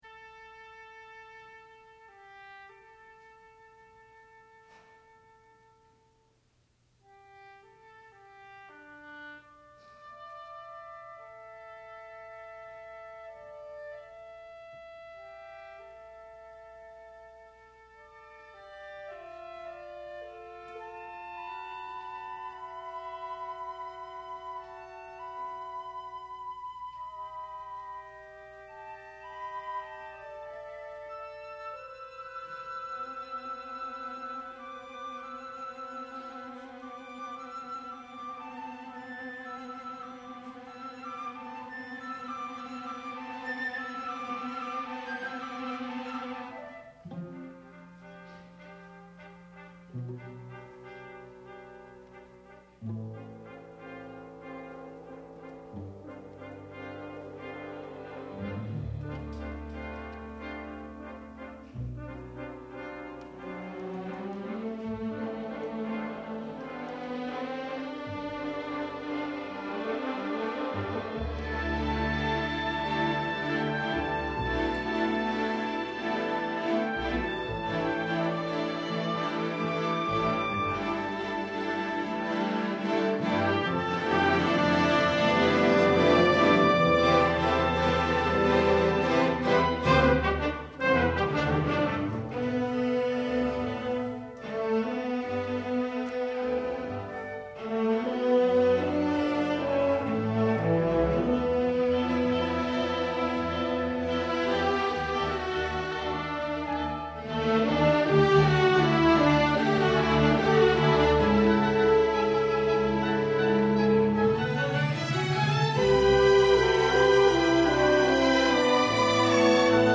for Orchestra (1980)